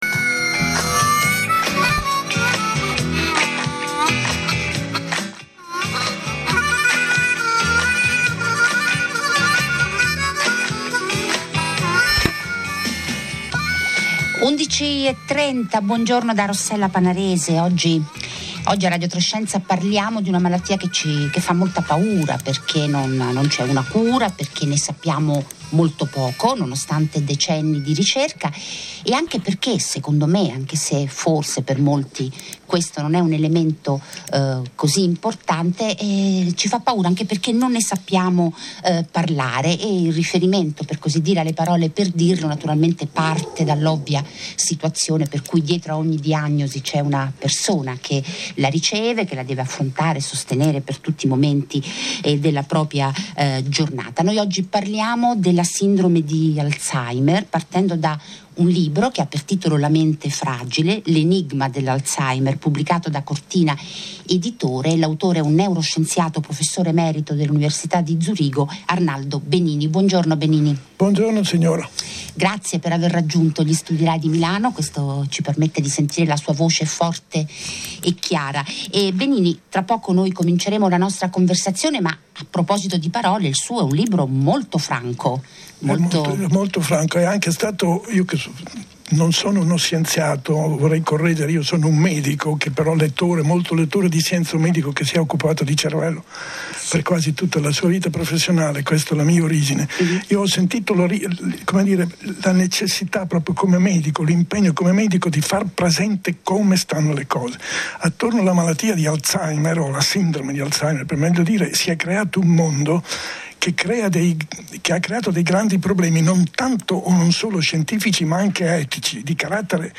AUDIO della trasmissione L’enigma dell’Alzheimer, Mercoledì 9 gennaio dalle 11.30 alle 12.00